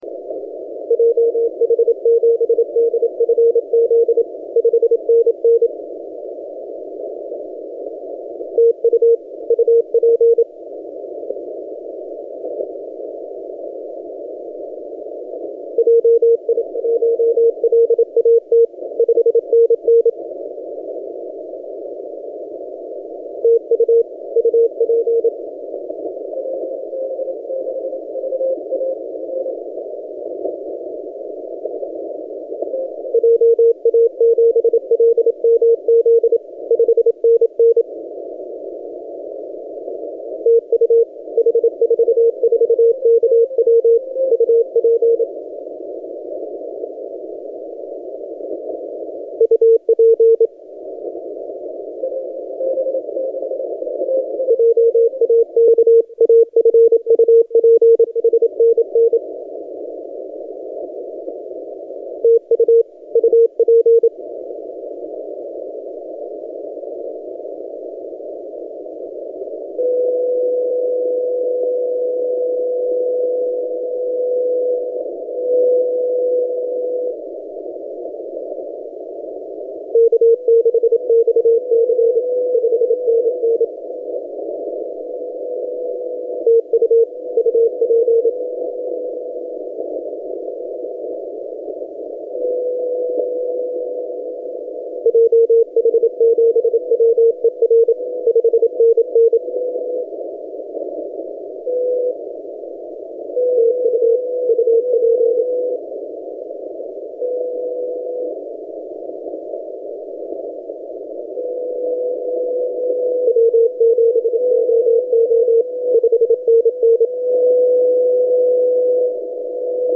H44KW 17 CW